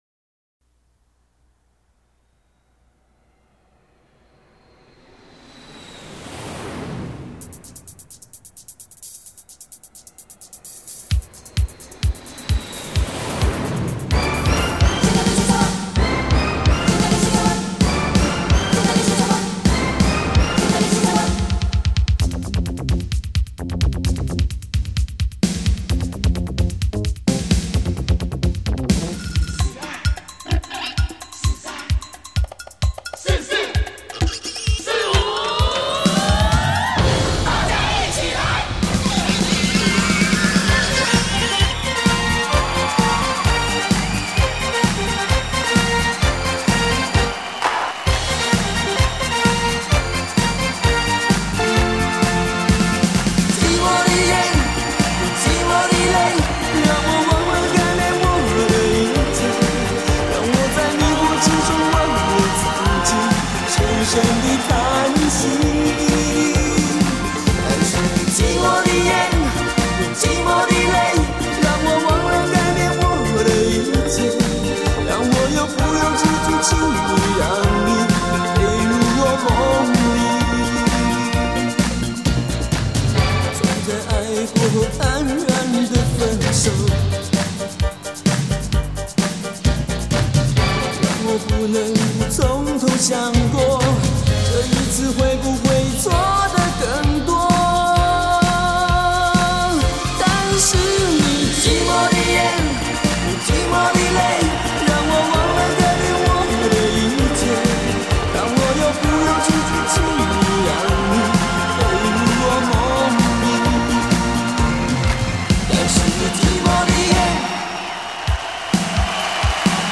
45就是 采45转快转的方式演唱串联当红歌曲的组曲 记录着70.80年代台湾流行乐史